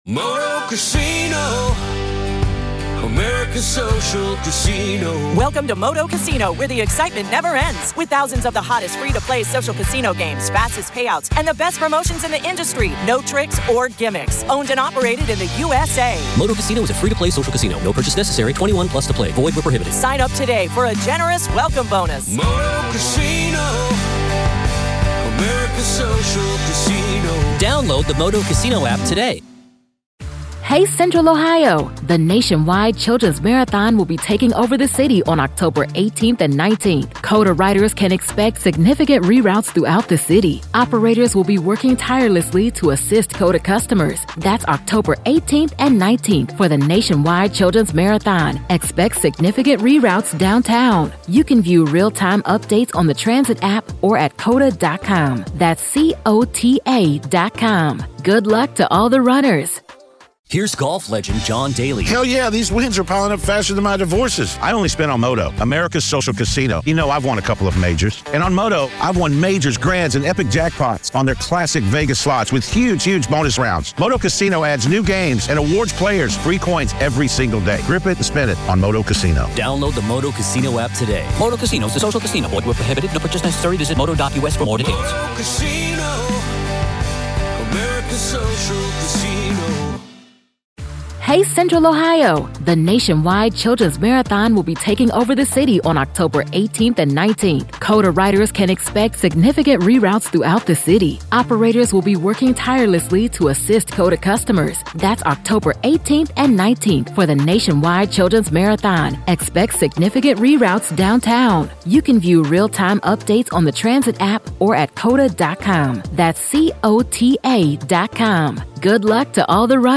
They scrutinize the systemic and social failures that allowed such a significant delay in recognizing her disappearance. This in-depth conversation explores the implications of the case, shedding light on societal responsibility and the mechanisms in place to protect our children.